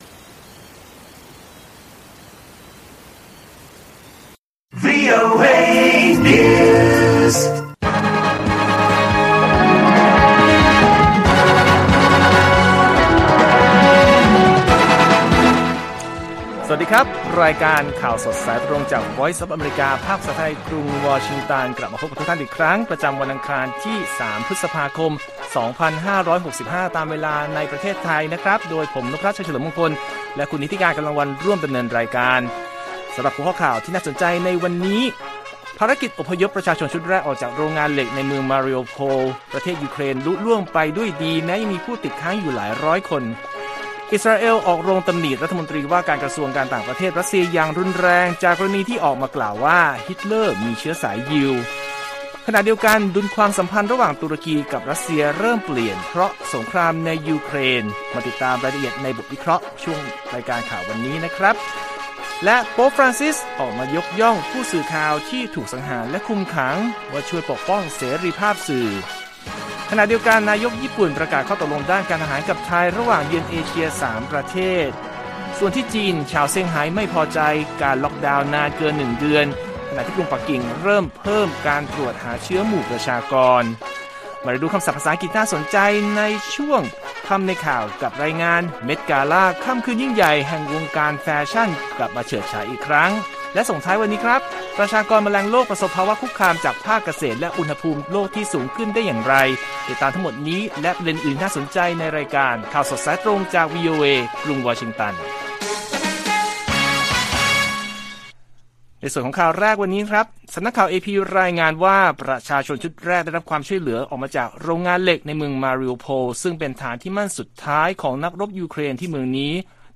ข่าวสดสายตรงจากวีโอเอ ไทย 6:30 – 7:00 น.วันที่ 3 พ.ค.65